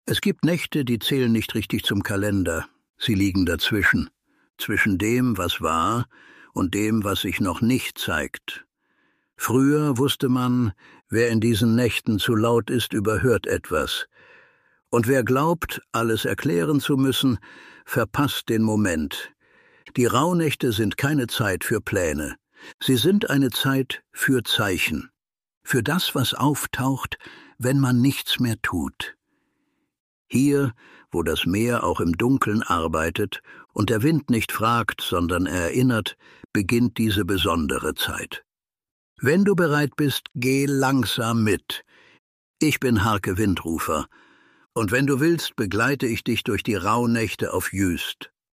die zwölf Raunächte mit kurzen, erzählten Geschichten – leise,
ruhig und nah an der Nordsee.